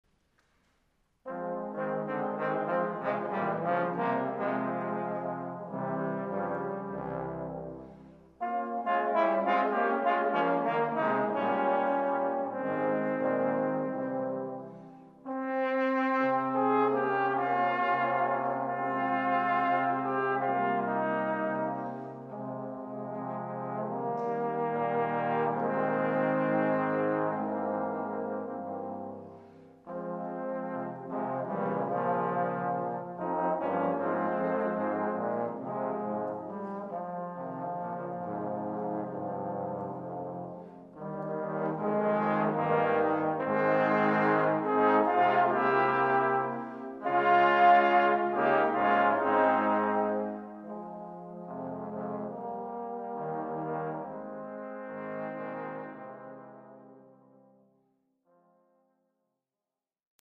Instrumentalnoten für Posaune PDF